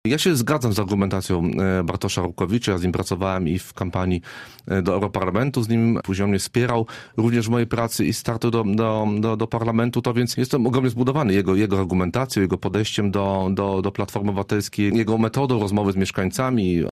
Wybory w PO. Komentarz M. Pabierowskiego
Tego ostatniego wspiera Marcin Pabierowski, miejski radny PO: